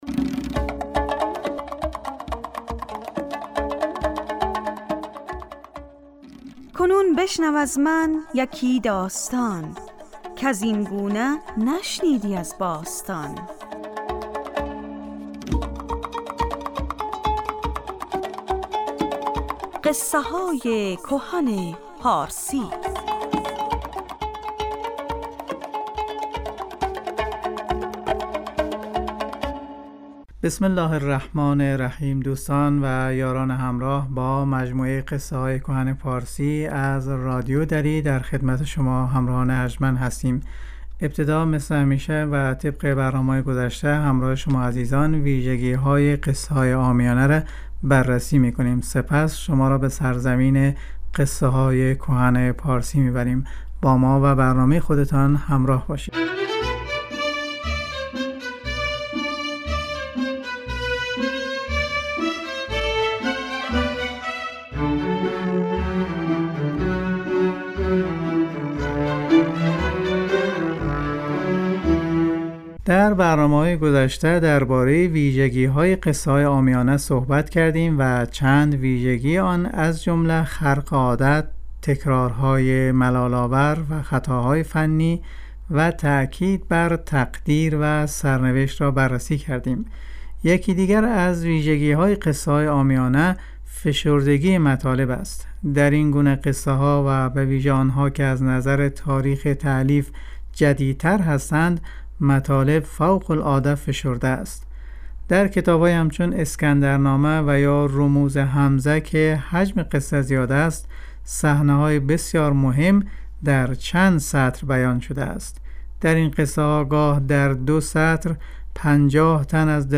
برنامه قصه های کهن پارسی جمعه ها ساعت 2.35 دقیقه به وقت ایران پخش می شود.